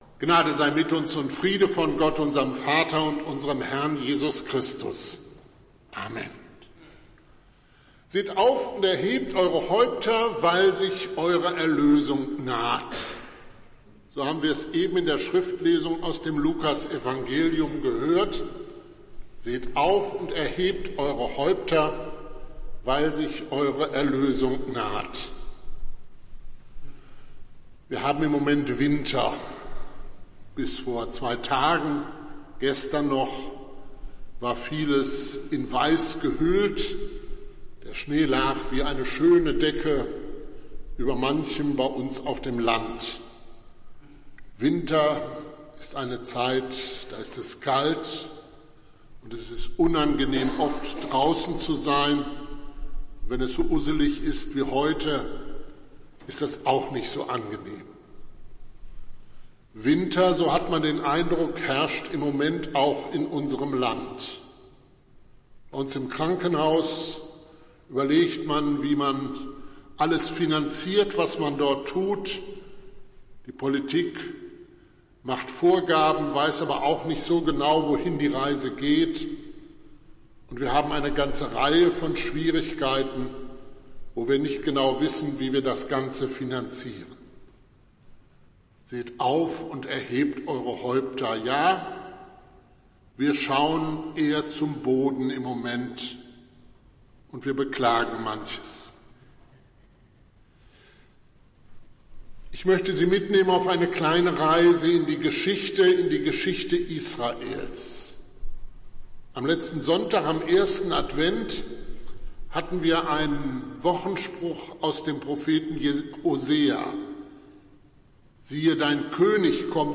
Predigt des Gottesdienstes aus der Zionskirche vom Sonntag, 12. Dezember 2023